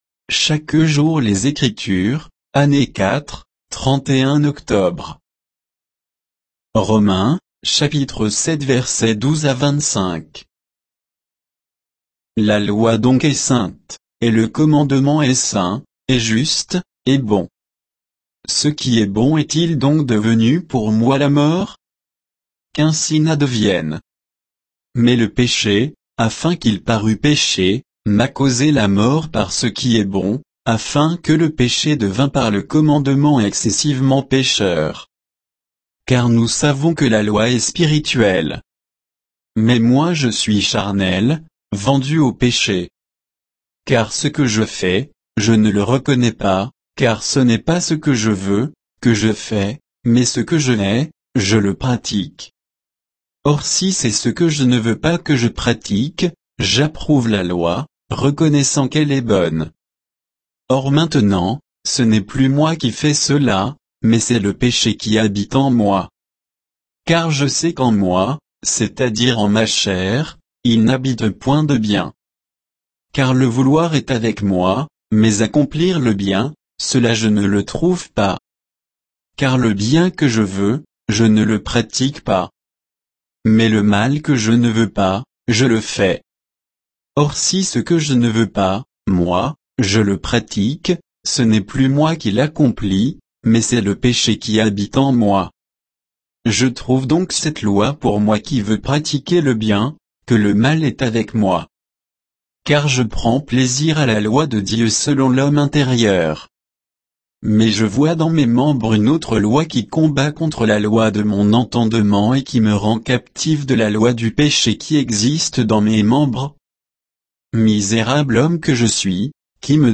Méditation quoditienne de Chaque jour les Écritures sur Romains 7